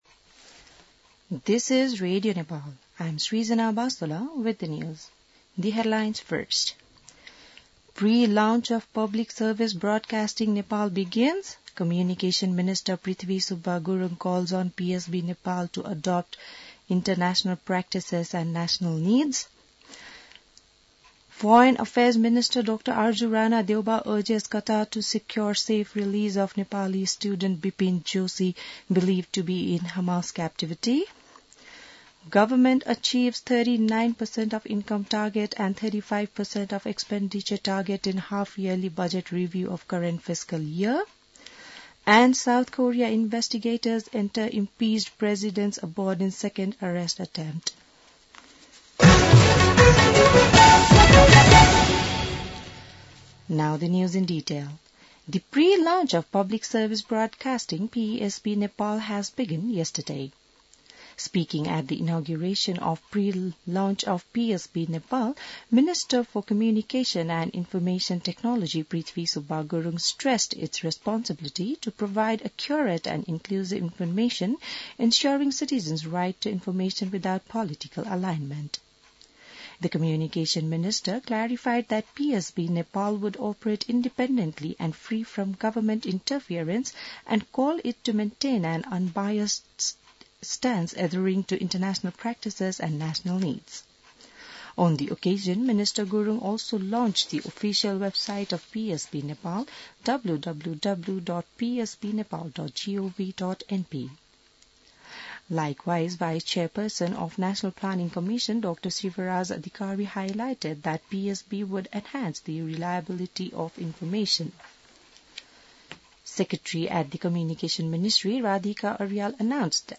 बिहान ८ बजेको अङ्ग्रेजी समाचार : ३ माघ , २०८१